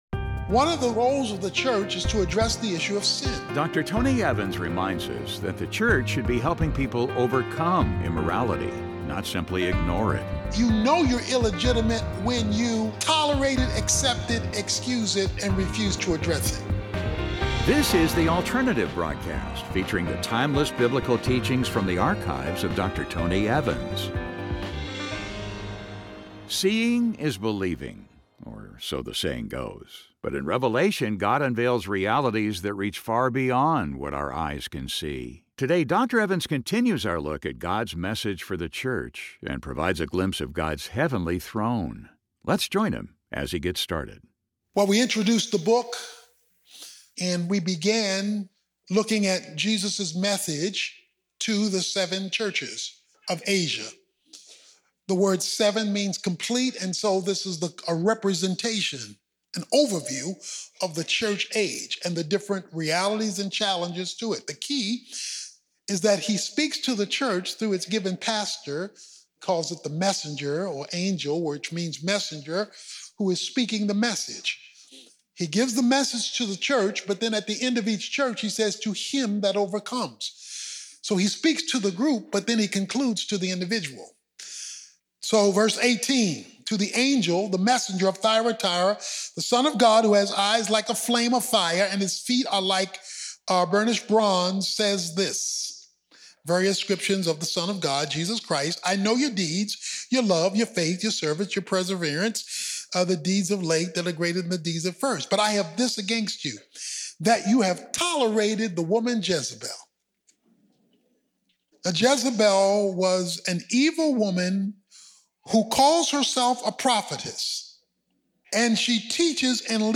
But in Revelation, God unveils realities that reach far beyond what our eyes can see. In this message, Dr. Tony Evans provides a picture of God's heavenly throne as revealed in the pages of Revelation.